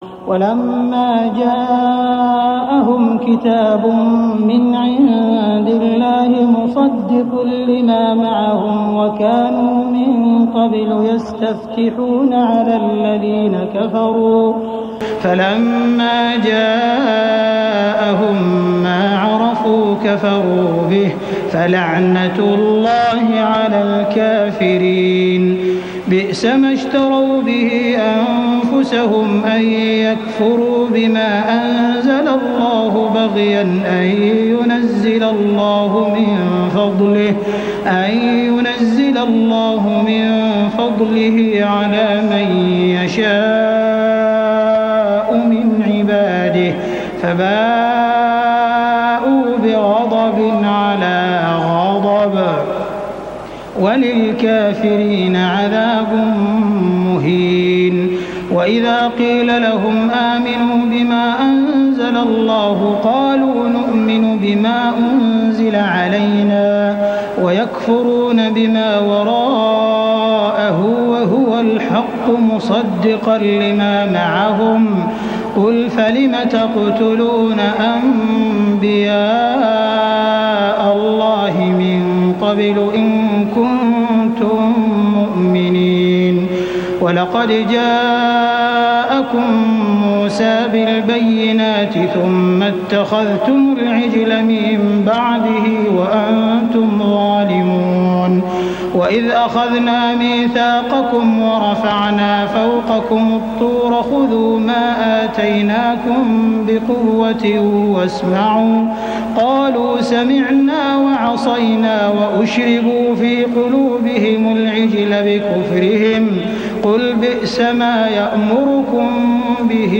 تهجد ليلة 27 رمضان 1414هـ من سورة البقرة (89-123) Tahajjud 27 st night Ramadan 1414H from Surah Al-Baqara > تراويح الحرم المكي عام 1414 🕋 > التراويح - تلاوات الحرمين